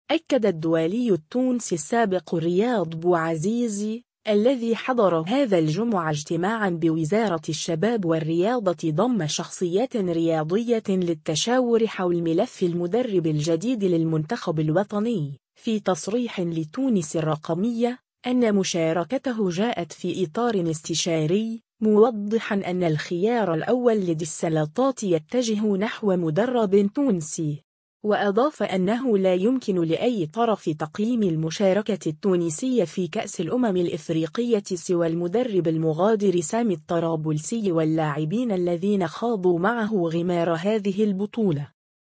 أكّد الدولي التونسي السابق رياض بوعزيزي، الذي حضر هذا الجمعة اجتماعًا بوزارة الشباب والرياضة ضمّ شخصيات رياضية للتشاور حول ملف المدرب الجديد للمنتخب الوطني، في تصريح لـ«تونس الرقمية»، أن مشاركته جاءت في إطار استشاري، موضحًا أن «الخيار الأول لدى السلطات يتجه نحو مدرب تونسي».